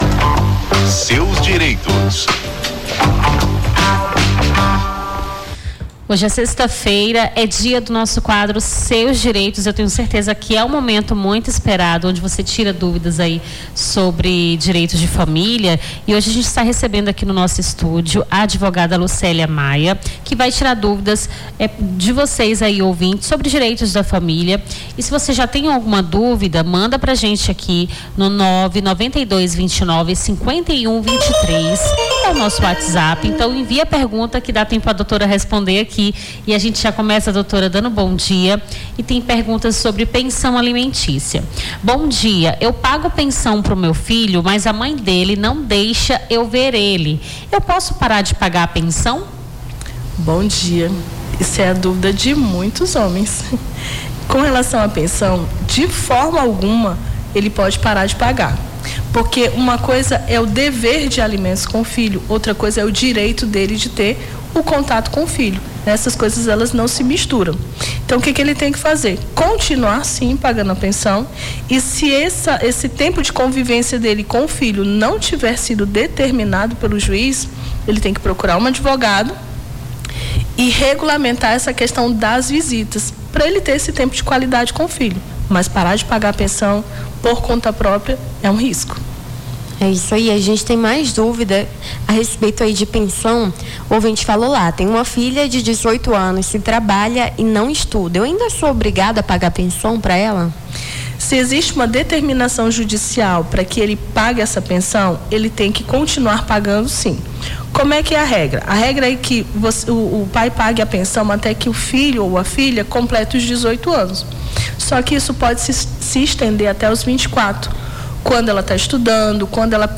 Rio Branco
Seus Direitos: advogada esclarece dúvidas dos ouvintes sobre direito de família